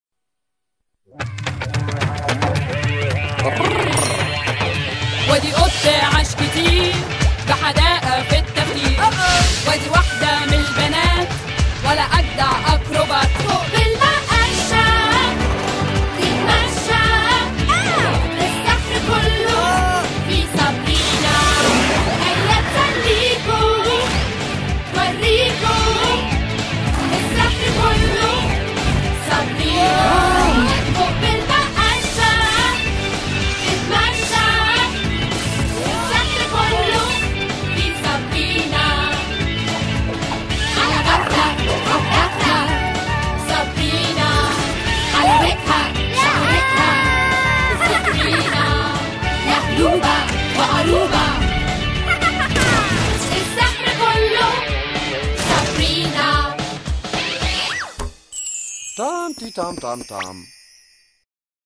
صابرينا - الحلقة 1 مدبلجة